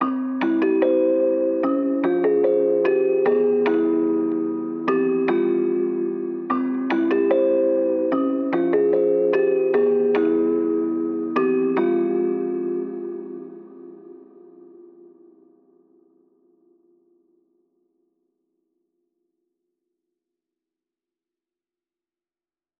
(Bells) NightCrawler_148Bpm.wav